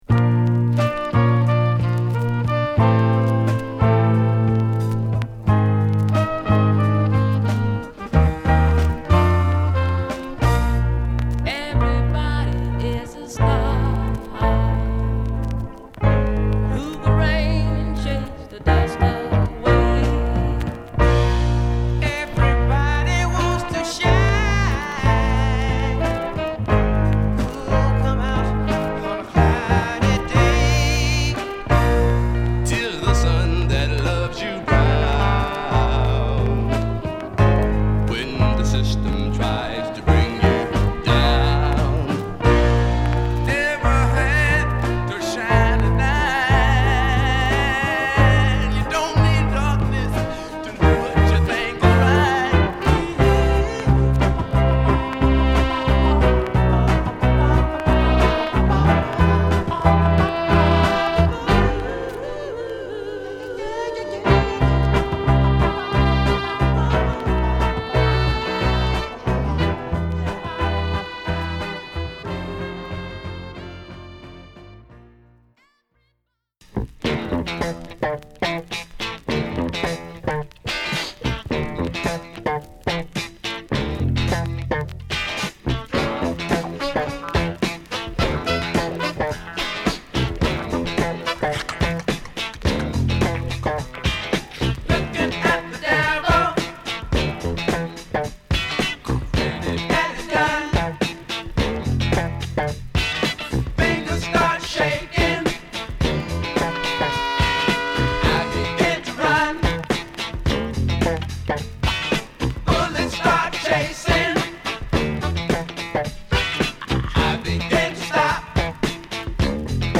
後半に向けて希望の光が差し込むようなアレンジに、公民権運動期らしいリリックが沁みます。
ファンキーなベースが弾む